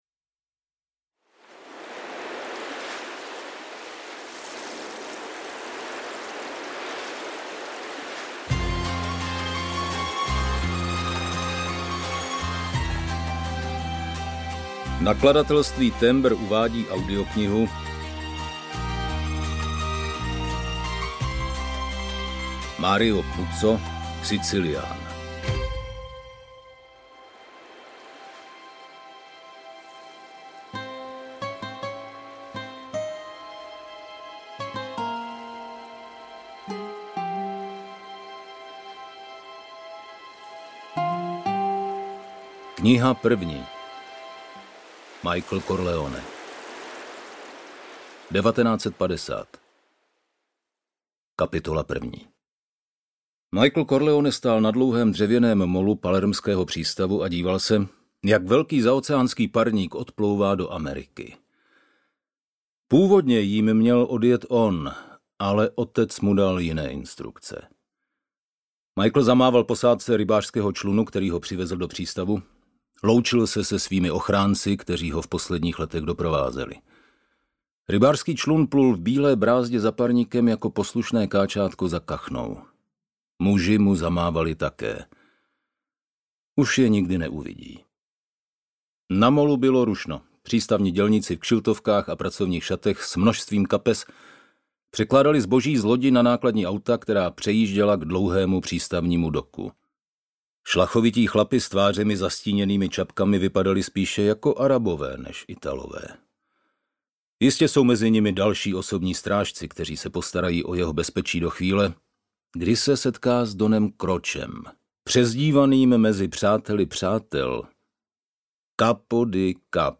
Sicilián audiokniha
Ukázka z knihy
• InterpretMarek Vašut